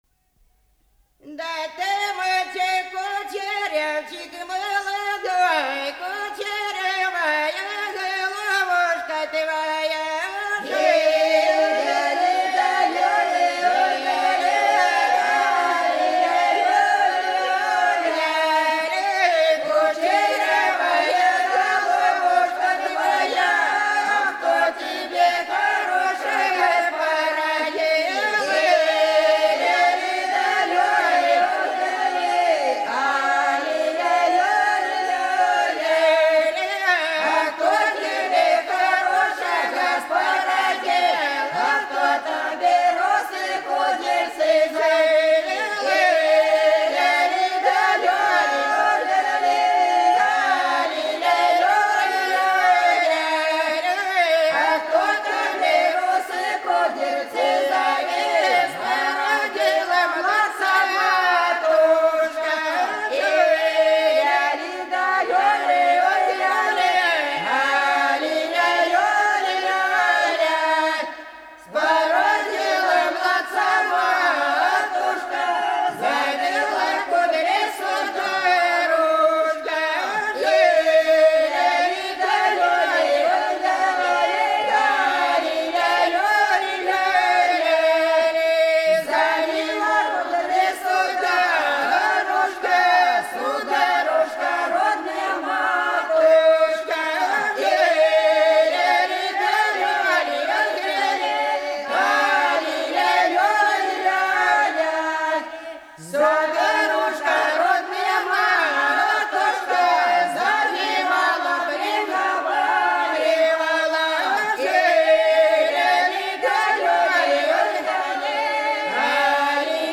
Голоса уходящего века (Курское село Илёк) Да ты мальчик-кучерявчик молодой (плясовая)